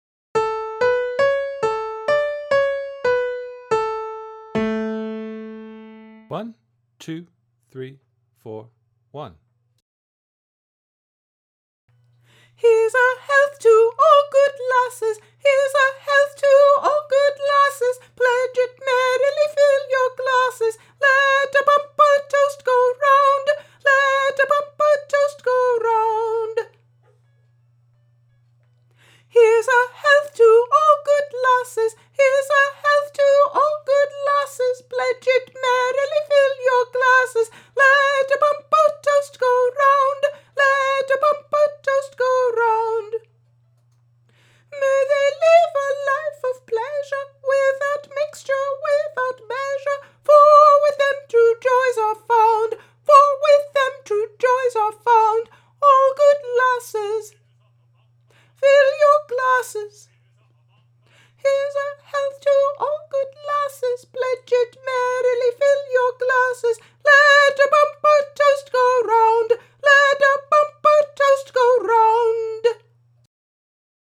A Celebrated Glee!
Alto Audio Part
Heres-A-Health-to-All-Good-Lasses_alto.mp3